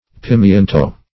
Pimiento \Pi*mien"to\, n. [Sp.]